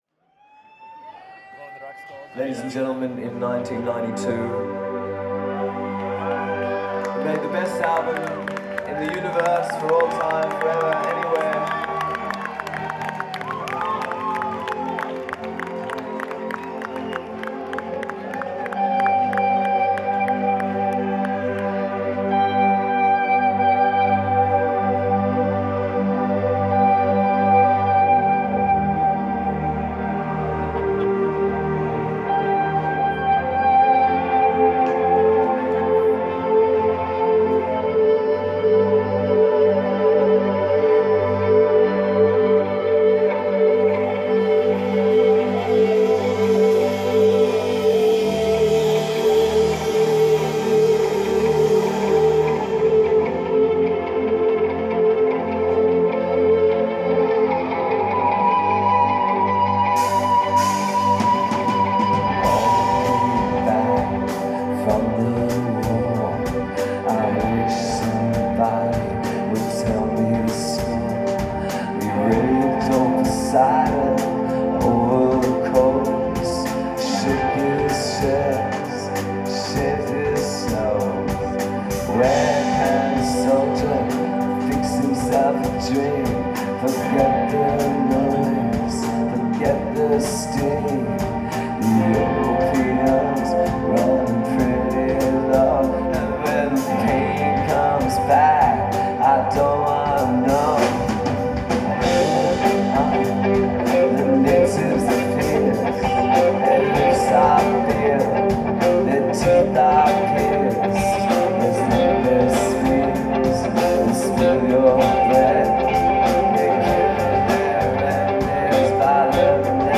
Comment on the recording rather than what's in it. in Foxboro, MA